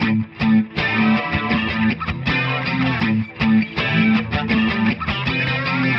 描述：短暂的时髦的A调吉他弹奏
Tag: 80 bpm Funk Loops Guitar Electric Loops 1.01 MB wav Key : A